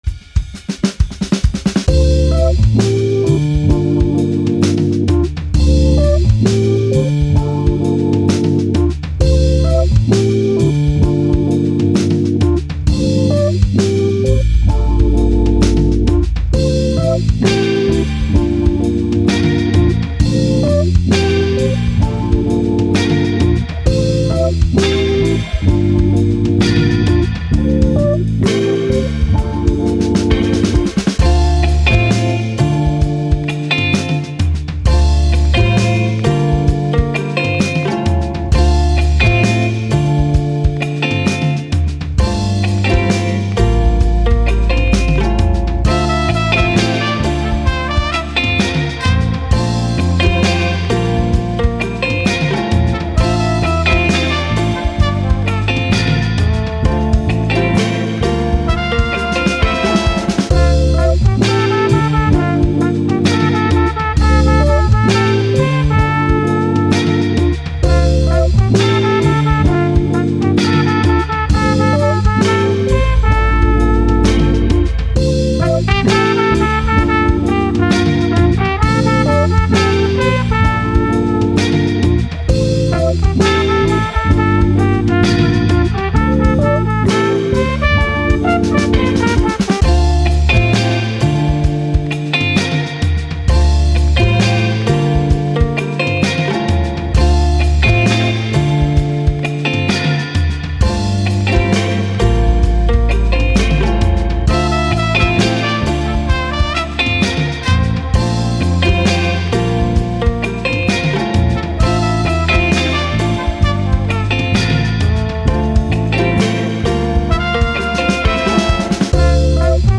Soul, Lofi, Chill, Vibe